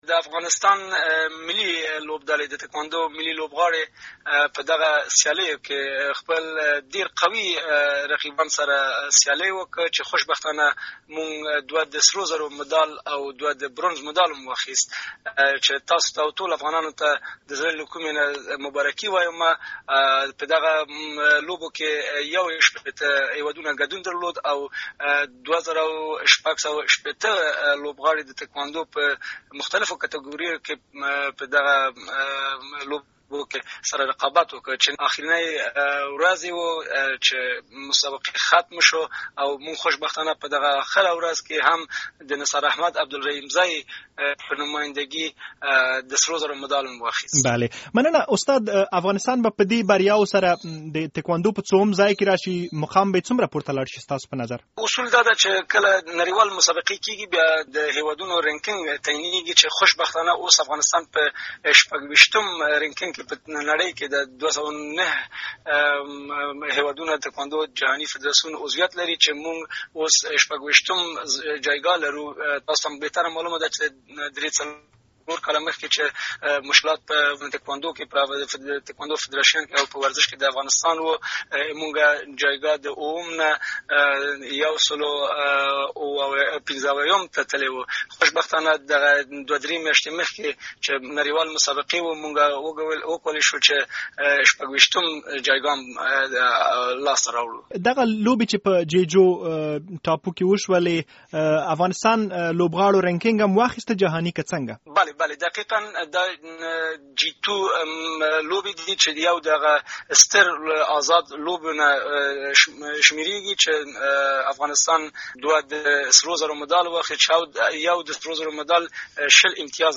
ورزشي مرکې